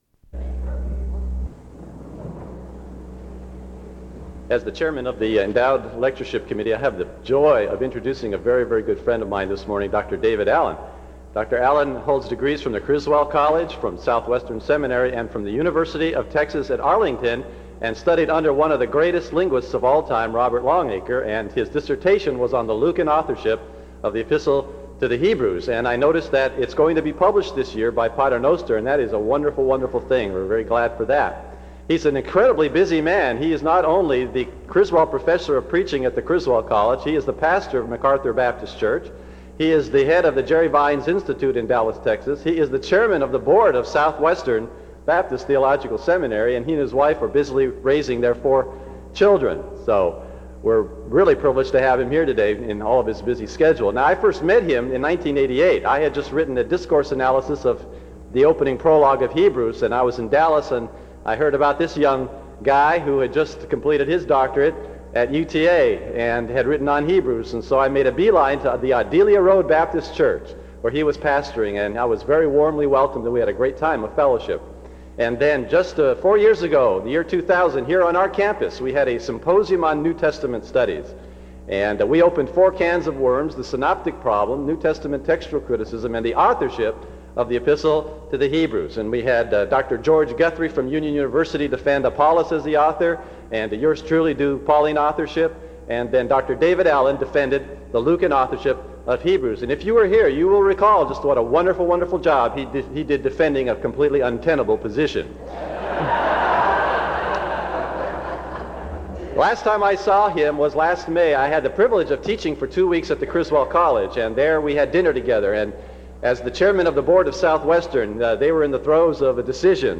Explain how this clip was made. SEBTS Chapel and Special Event Recordings